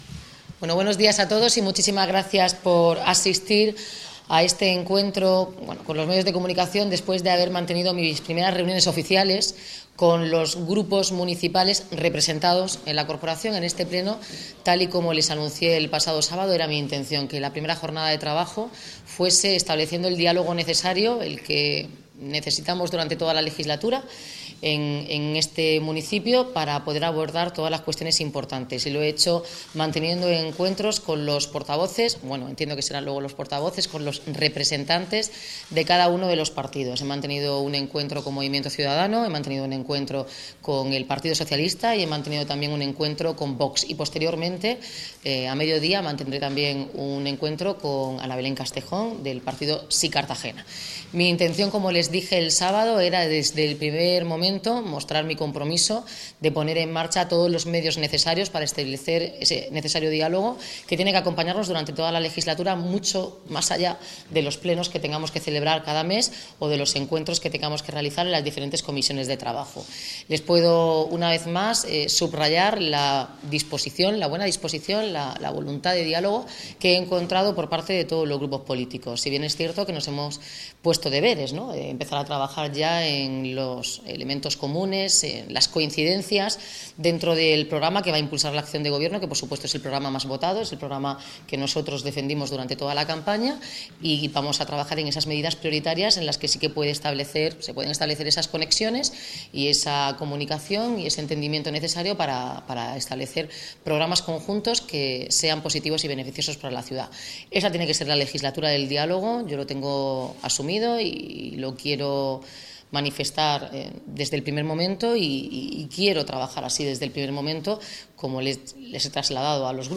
Declaraciones Noelia Arroyo tras la ronda de contactos Declaraciones de Jesús Giménez Gallo, concejal de Movimiento Ciudadano.